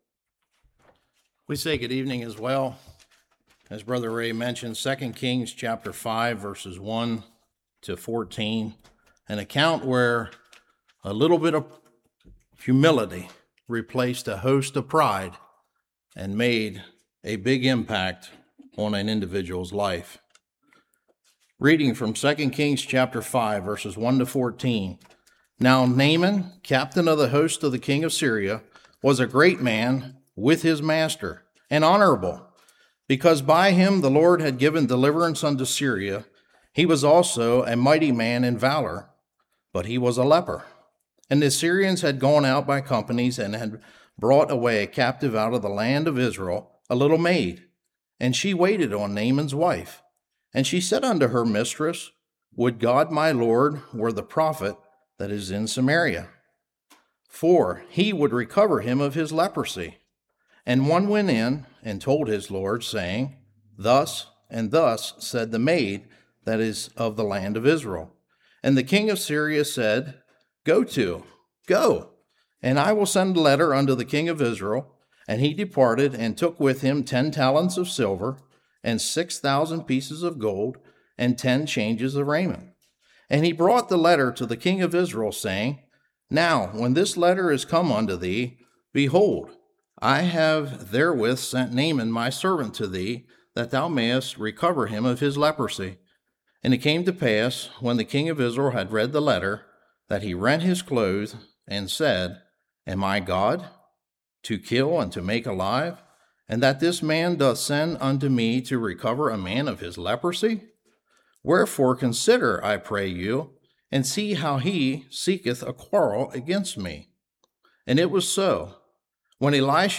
2 Kings 5:1-14 Service Type: Evening How often are we available to serve God?